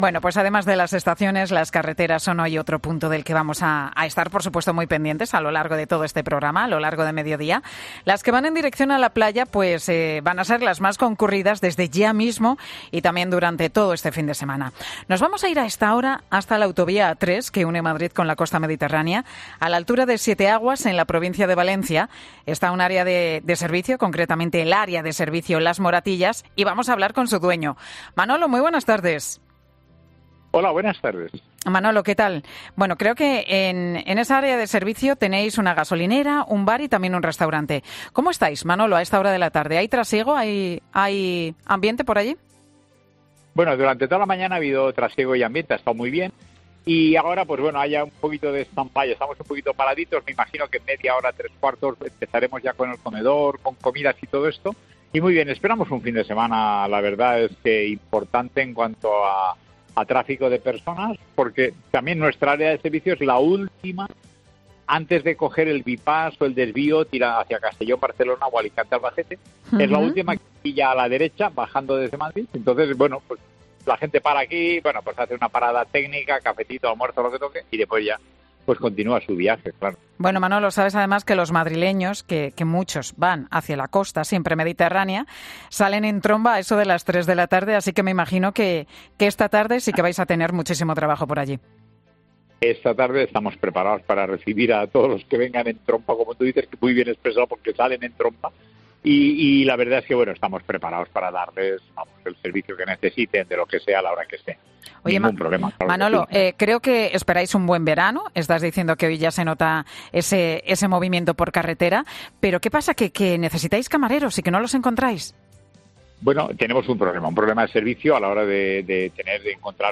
AUDIO: Escucha la entrevista al dueño de una estación de servicio en 'Mediodía COPE'